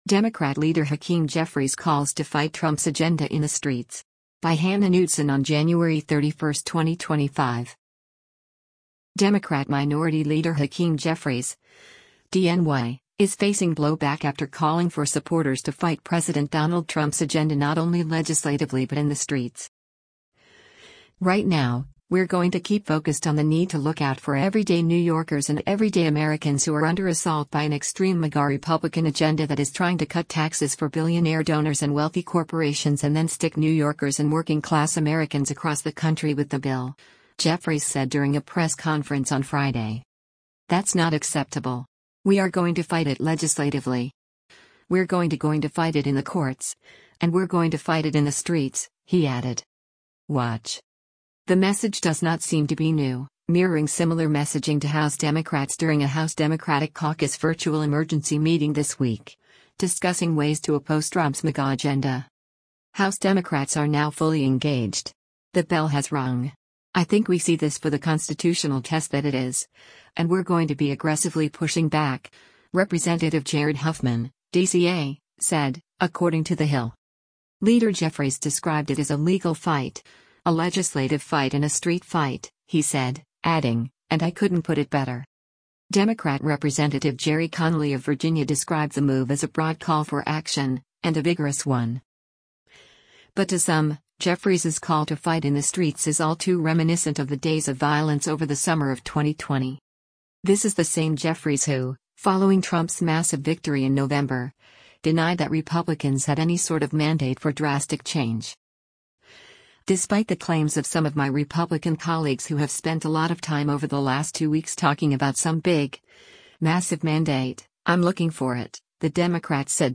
“Right now, we’re going to keep focused on the need to look out for everyday New Yorkers and everyday Americans who are under assault by an extreme MAGA Republican agenda that is trying to cut taxes for billionaire donors and wealthy corporations and then stick New Yorkers and working class Americans across the country with the bill,” Jeffries said during a press conference on Friday.